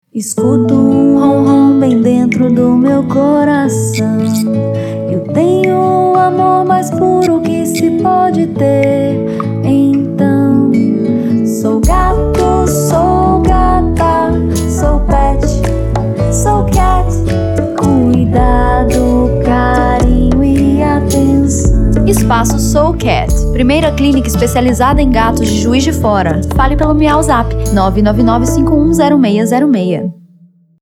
Nós adoramos nosso Jingle!